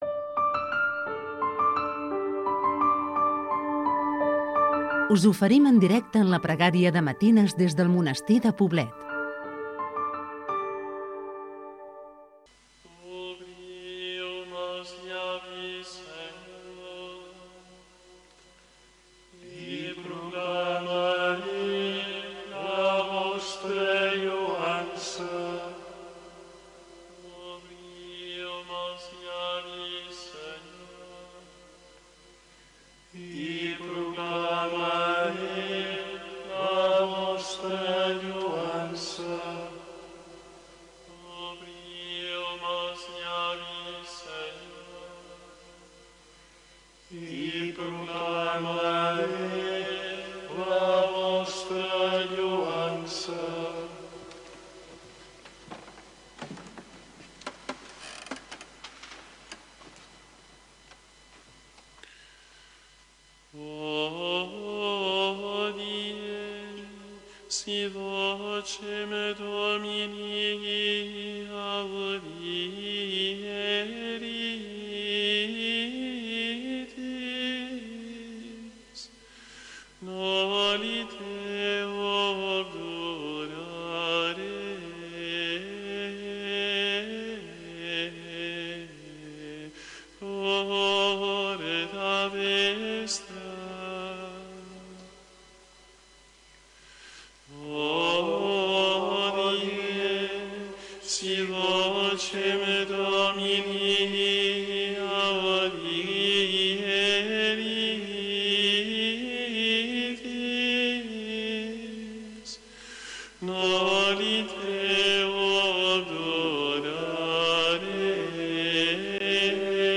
Des del Reial Monestir Cistercenc de Santa Maria de Poblet